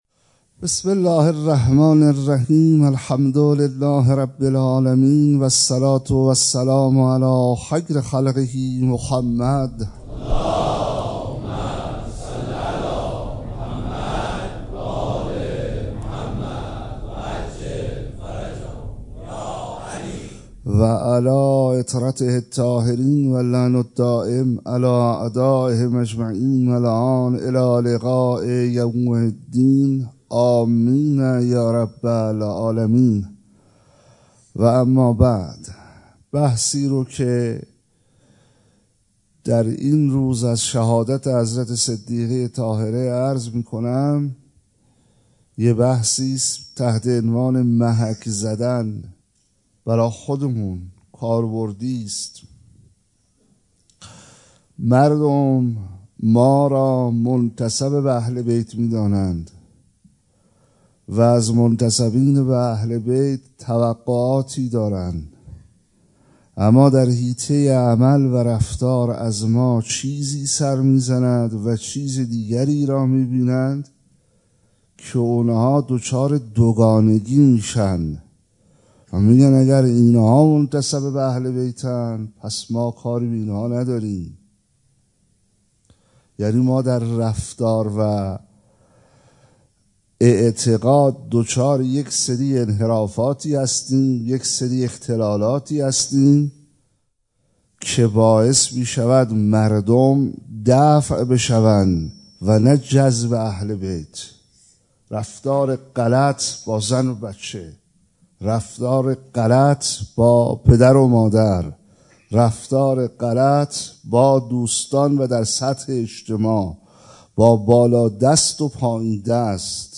20 بهمن 97 - مسجد حضرت امیر - نشانه‌های محبین
سخنرانی